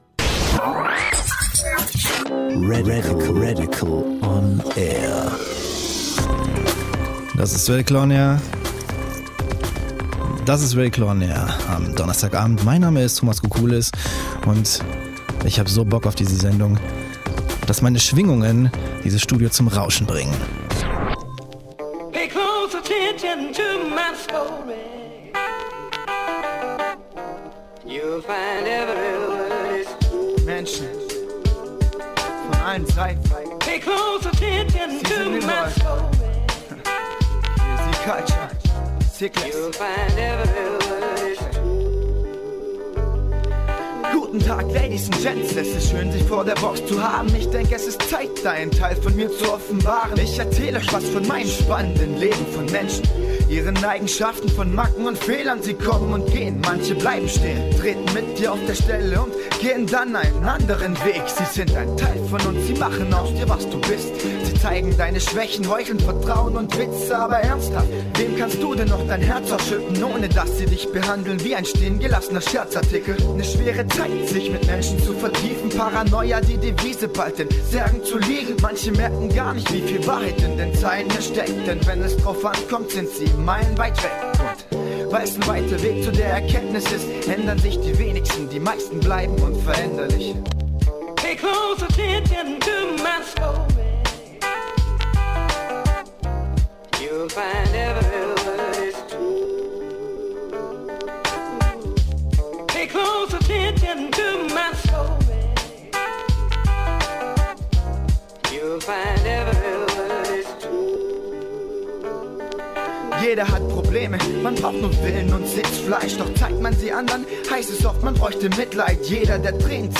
live im Studio JT & the Fonky Monkeys